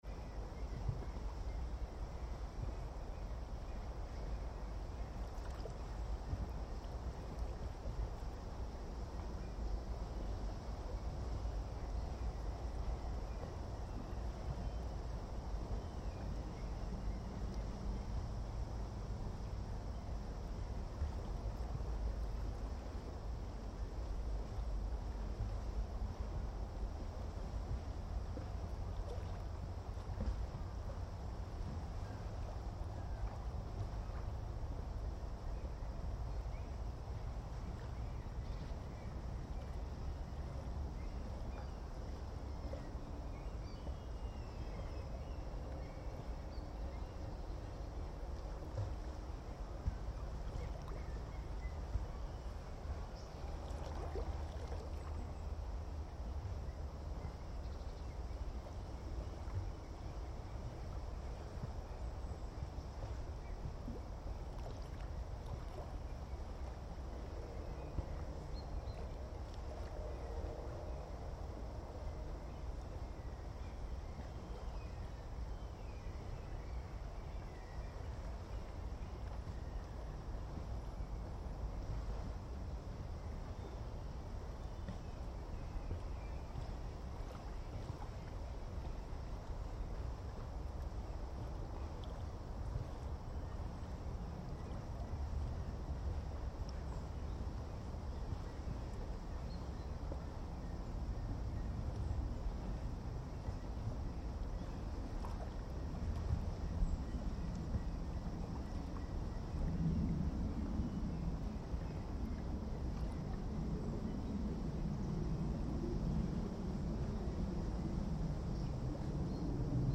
The recording contains the ambience at "Kjærlighetsstien" at Utøya, close to Oslo, in the morning of 22nd July 2022.
The sound of the waves, forest, birds etc. is the same while recording as it was during the shooting 11 years before, minus the cries of pain and panic and shots.